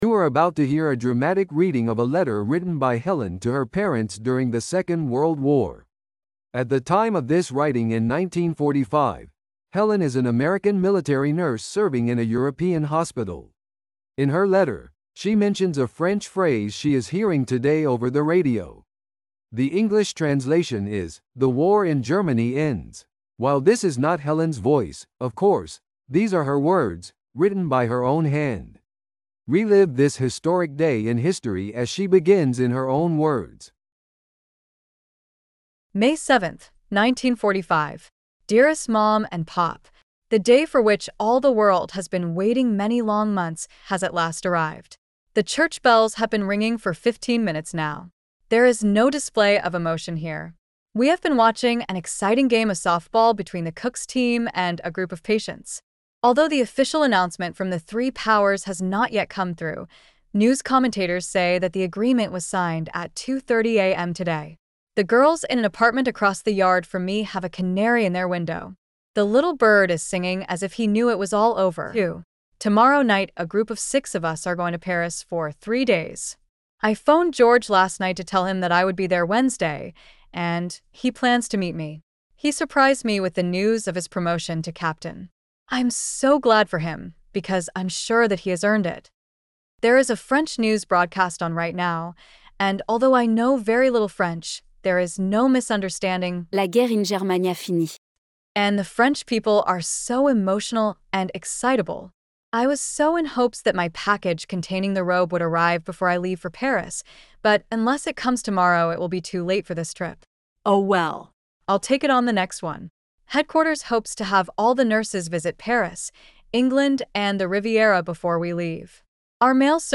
If you would like, you can read along with the narrator.